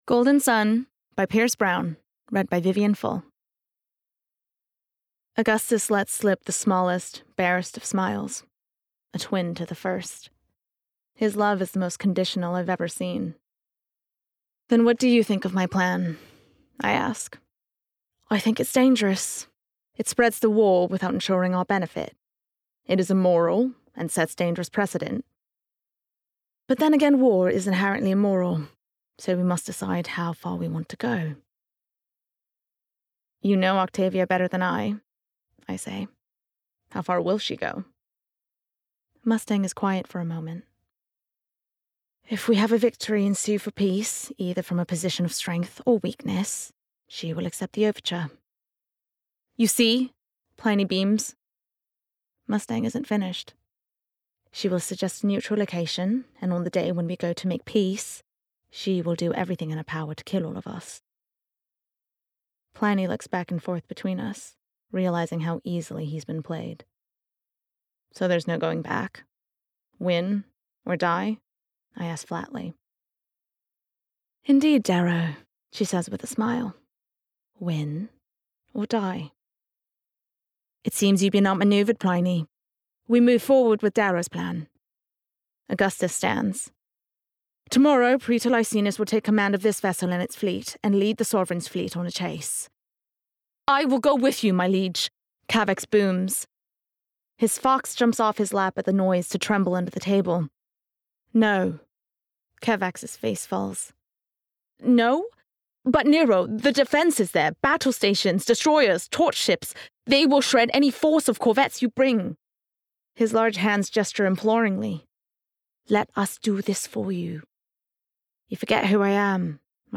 Versatile/Contemporary/Youthful
• Audio Books